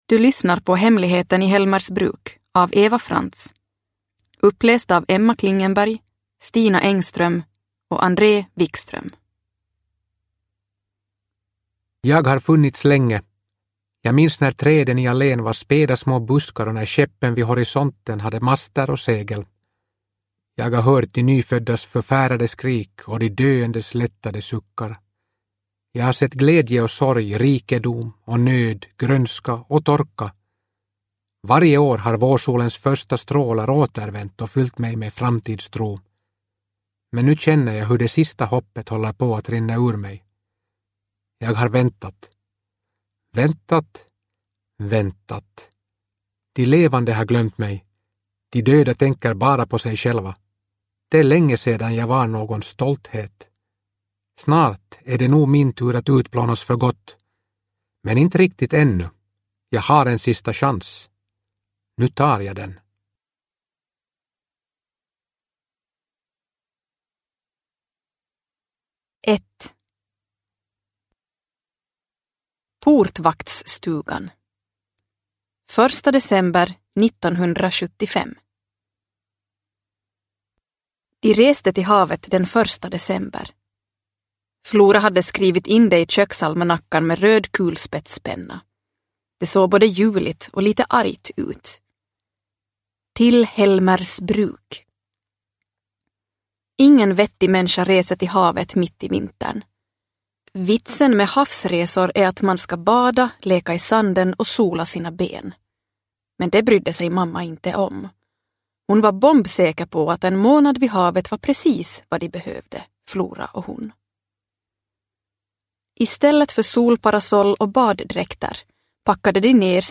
Hemligheten i Helmersbruk – Ljudbok – Laddas ner